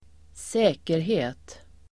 Uttal: [²s'ä:kerhe:t]